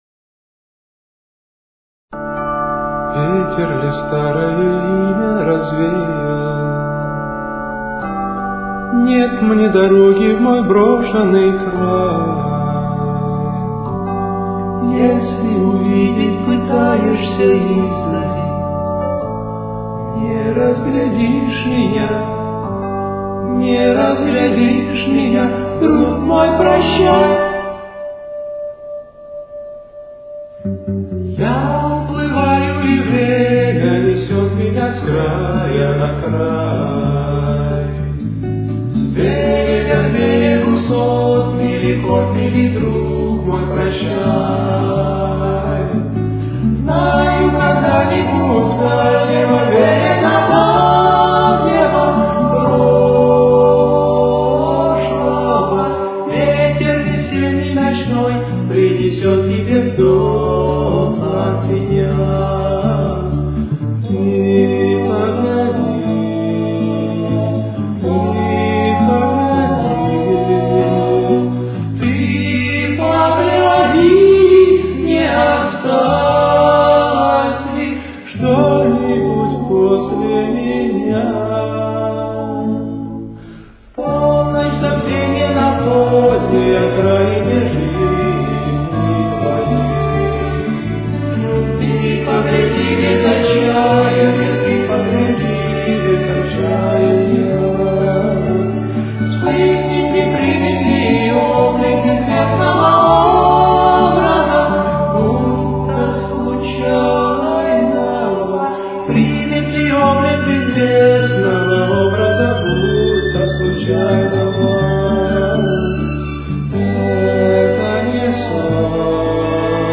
с очень низким качеством (16 – 32 кБит/с)
Ре мажор. Темп: 126.